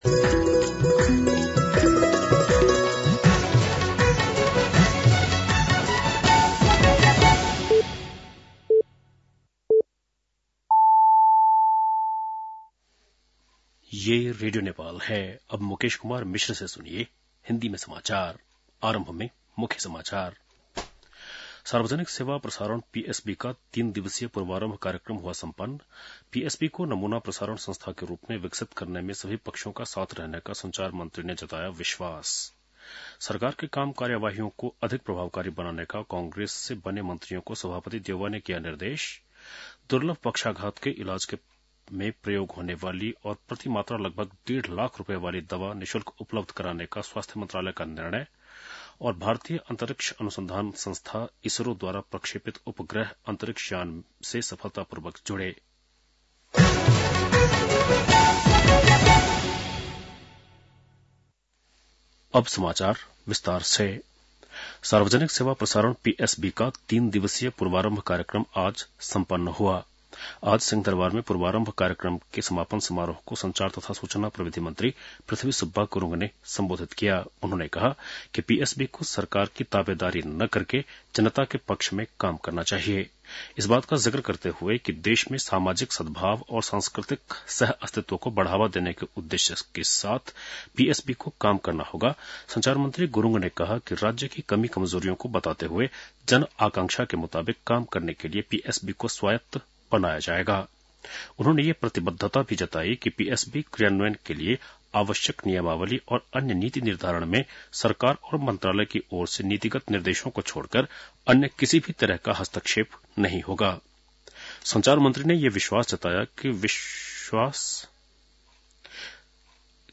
बेलुकी १० बजेको हिन्दी समाचार : ४ माघ , २०८१